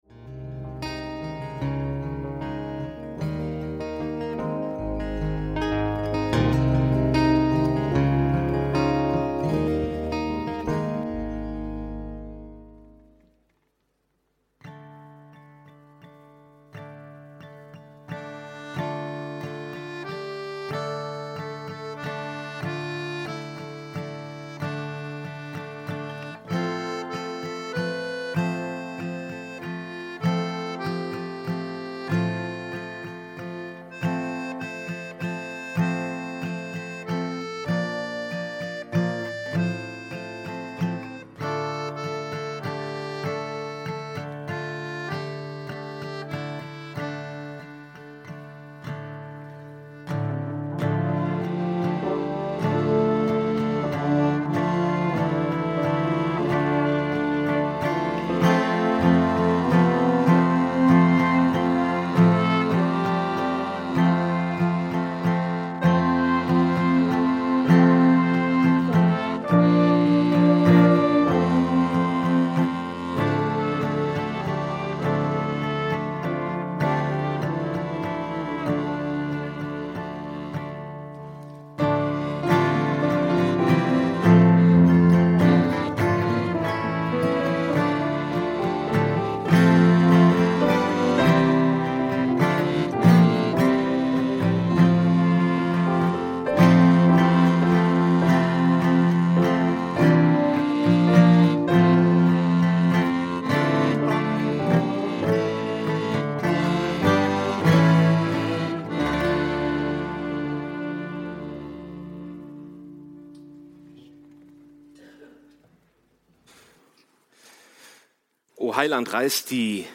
O Heiland reiß die Himmel auf – Predigt vom 07.12.2025